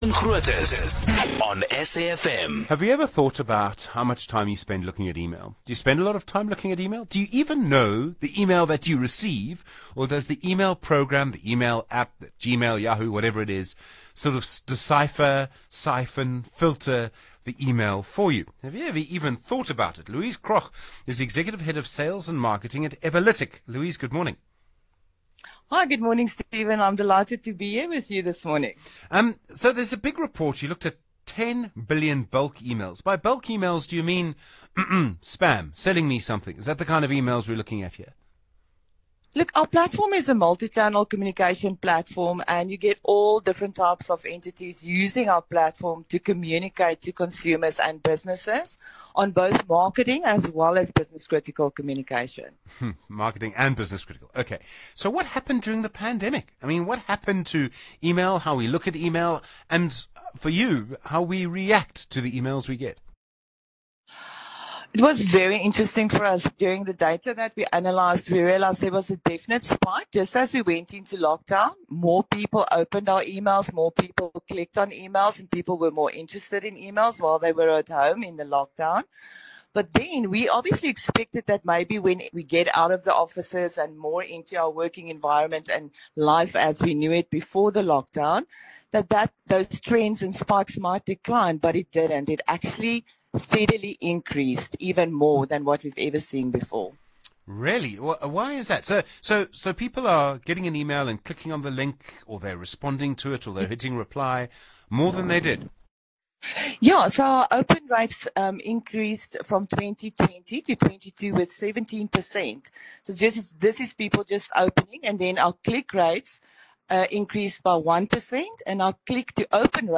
on SAFM about the 2022 Benchmarks Report.